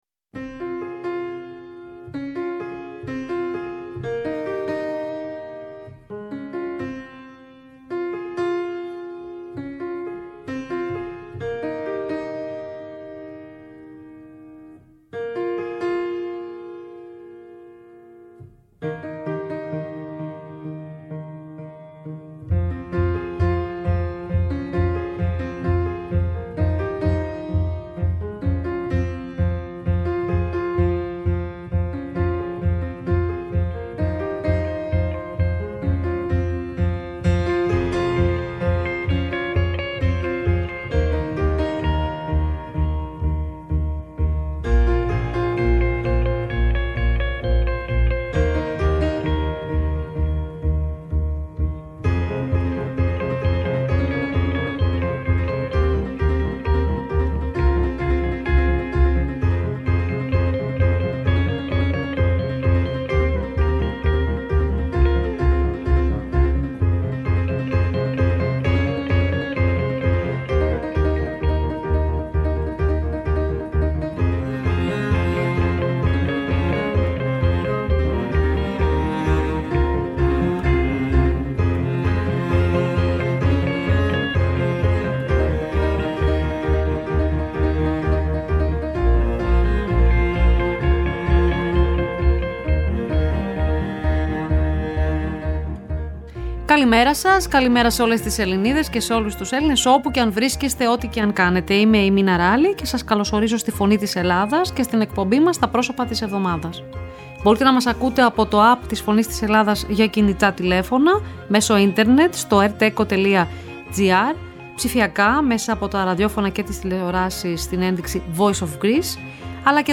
Η ΦΩΝΗ ΤΗΣ ΕΛΛΑΔΑΣ Τα Προσωπα Της Εβδομαδας ΣΥΝΕΝΤΕΥΞΕΙΣ Συνεντεύξεις Χρηστο Ζερεφο